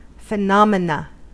Ääntäminen
IPA : /fɪ.ˈnɑm.ə.ˌnə/